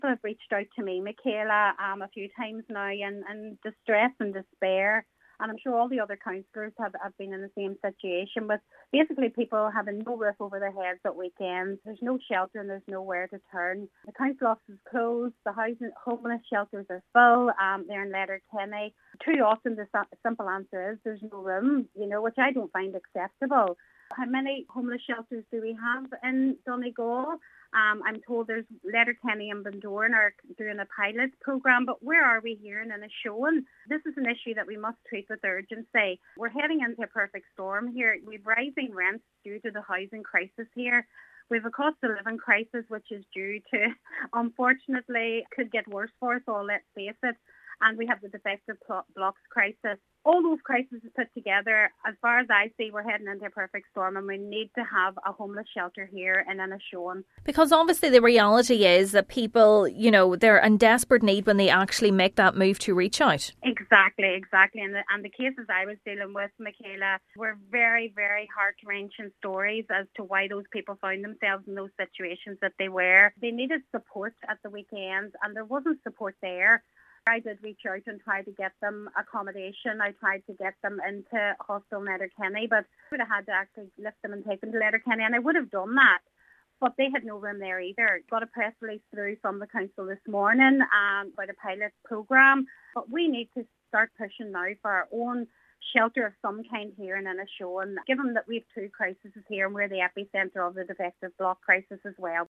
Councillor Joy Beard says she has been contacted by people in distress who are struggling to secure accommodation particularly during weekends as the local housing office operates Monday to Friday only and homeless shelters are full.
She says the county is heading into a perfect storm: